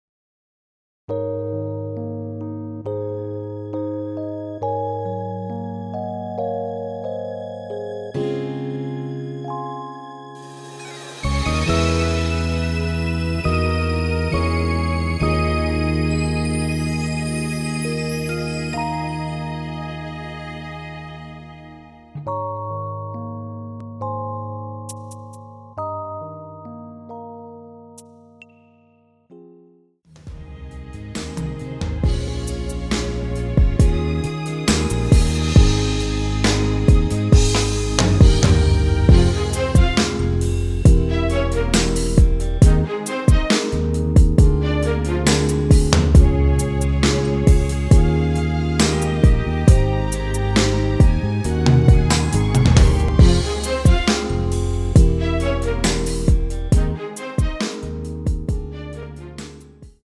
원키(1절+후렴)으로 진행되는 MR입니다.
Ab
앞부분30초, 뒷부분30초씩 편집해서 올려 드리고 있습니다.